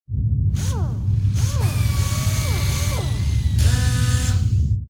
repair2.wav